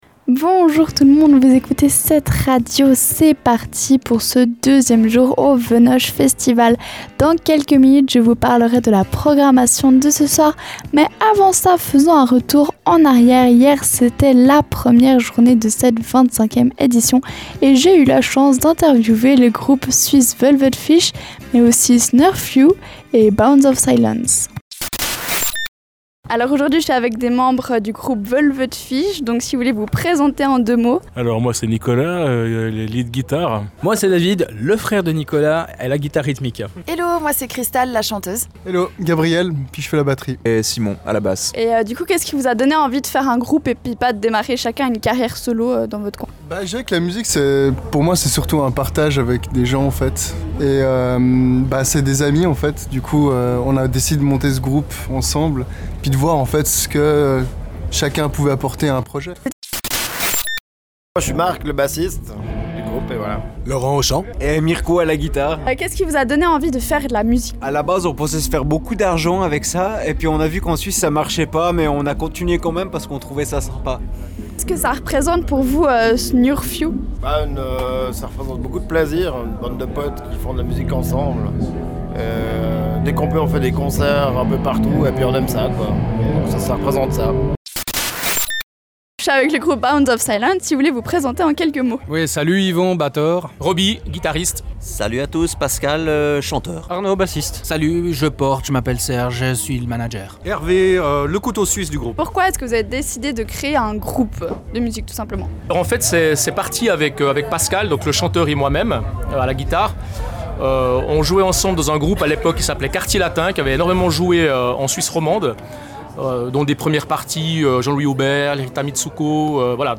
On fait un retour sur la première journée du Venoge avec l’interview de Velvetfisch, Bounds Of Silence, Snurfu, Sisters Of Mercy et Prophets Of Rage.